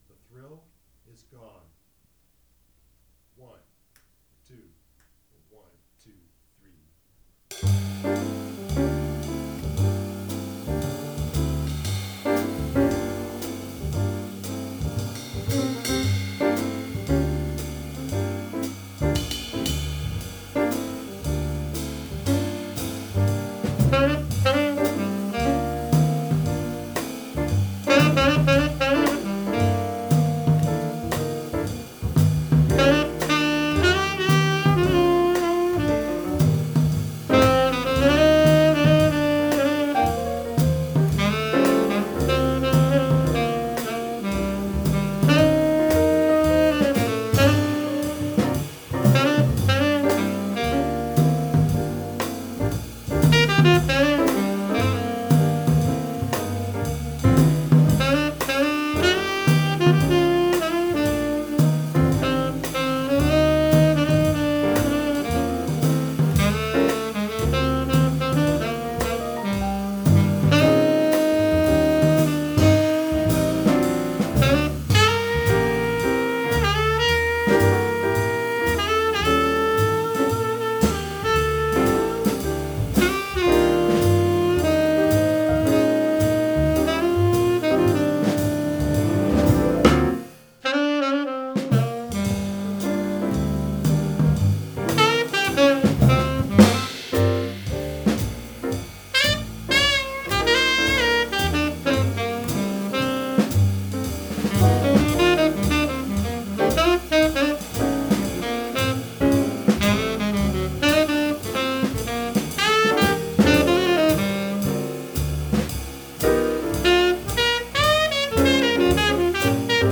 piano
bass
Recorded 31 January 2018 at Pro Musica, Chicago, Illinois
to a custom 8-track, 1/2" Studer/Revox C-278 at 15ips/lEC